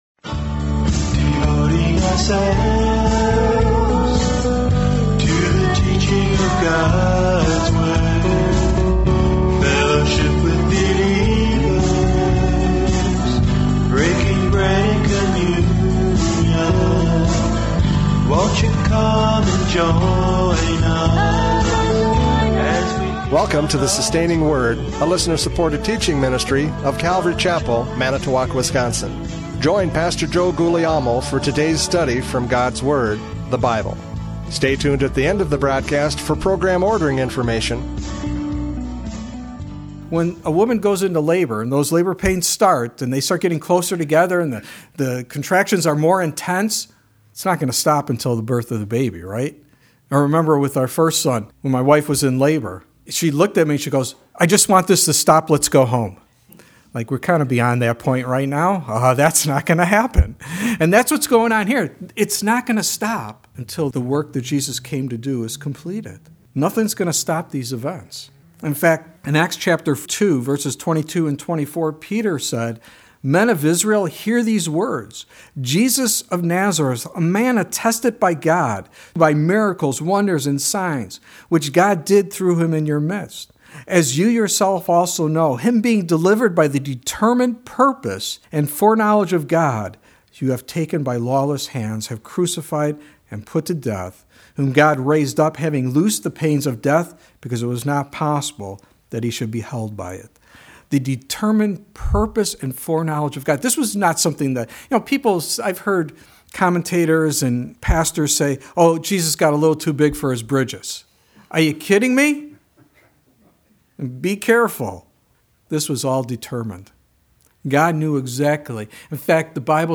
John 13:31-38 Service Type: Radio Programs « John 13:31-38 Listen Carefully!